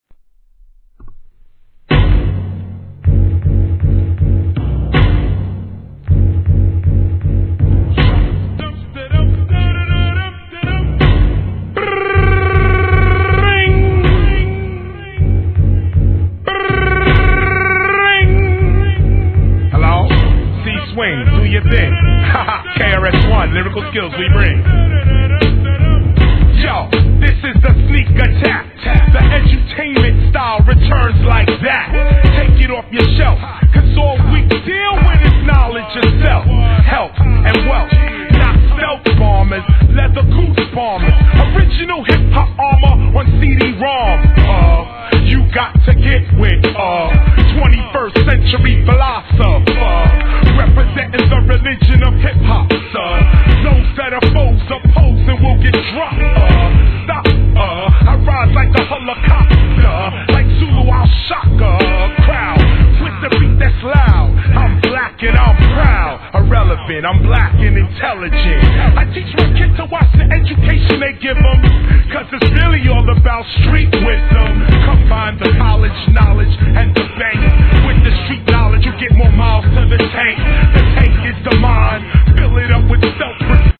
HIP HOP/R&B
スローながら極太ベースラインがうねる様なトラックがコアなHIP HOPファンにはたまりません！！